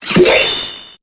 Cri de Dimoclès dans Pokémon Épée et Bouclier.